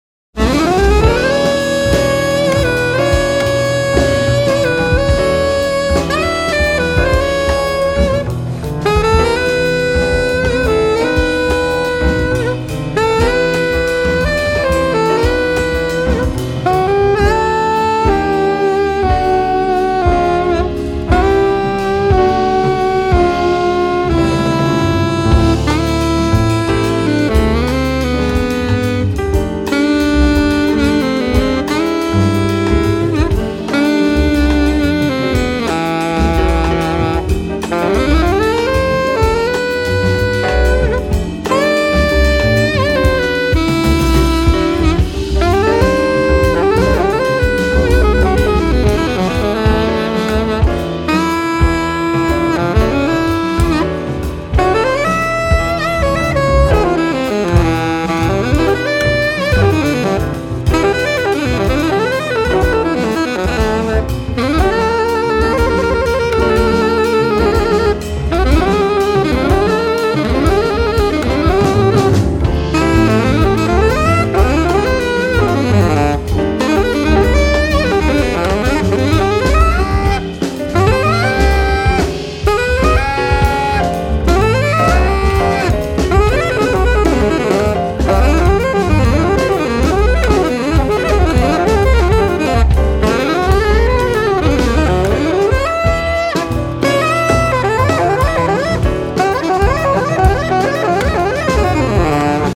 alto and sopranino saxophones
piano and keyboards
double bass
drums